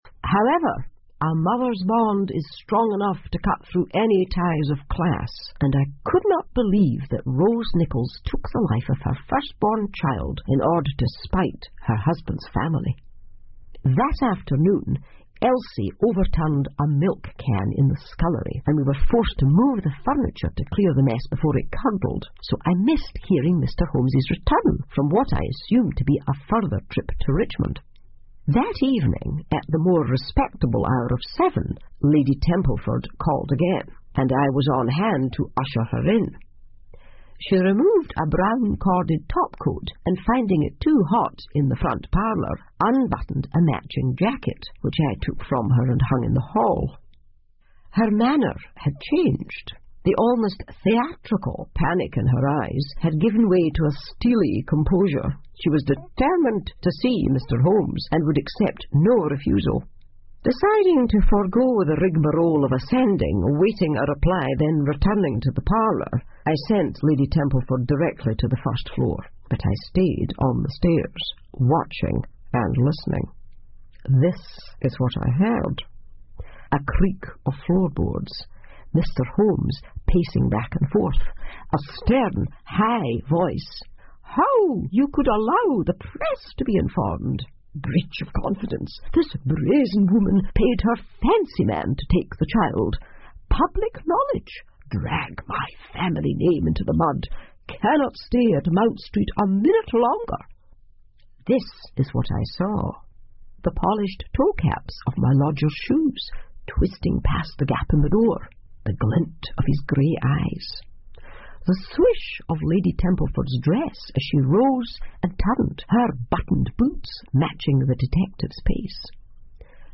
福尔摩斯广播剧 Cult-The Lady Downstairs 5 听力文件下载—在线英语听力室